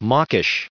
1685_mawkish.ogg